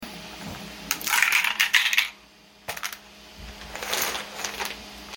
ghostsy bois asmr lol, use sound effects free download